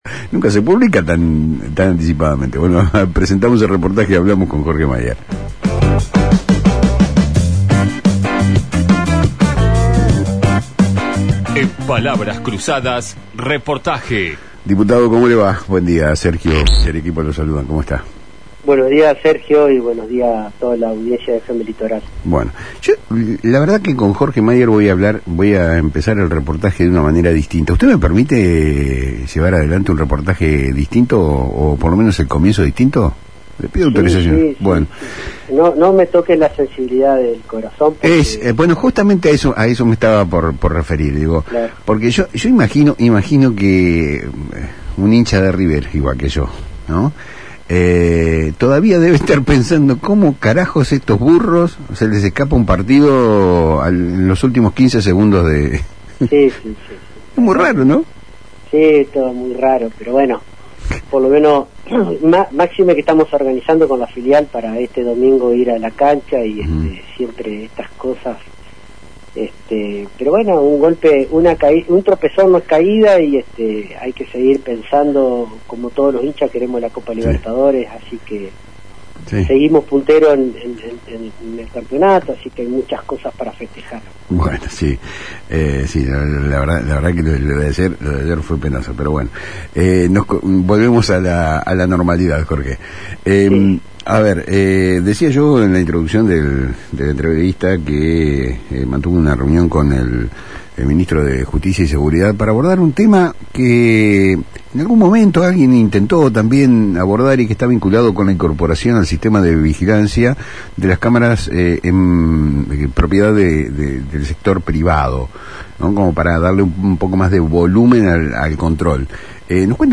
El diputado provincial Jorge Maier abordó esta mañana en FM Litoral dos temas cruciales para la agenda de la provincia: una novedosa iniciativa para integrar las cámaras de seguridad privadas a la red policial y la inminente discusión sobre un proyecto de endeudamiento para reestructurar las finanzas provinciales.